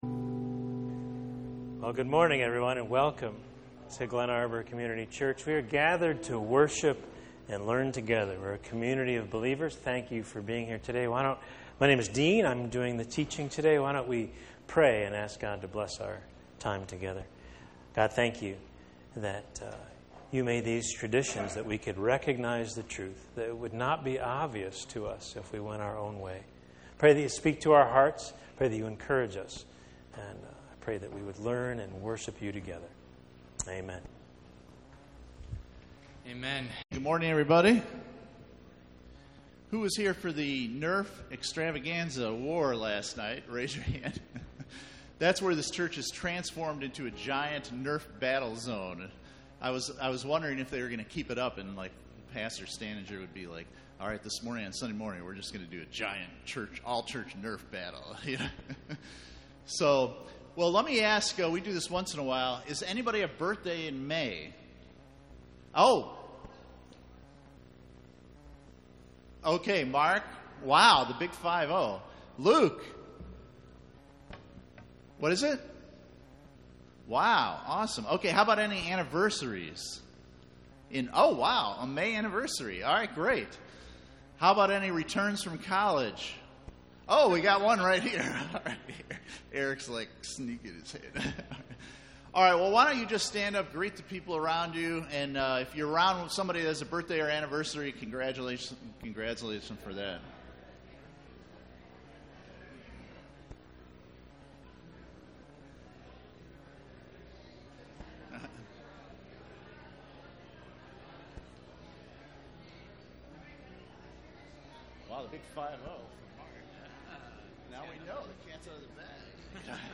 Ambasssadors in Polarized World Service Type: Sunday Morning %todo_render% « The God of War and Peace God and Marriage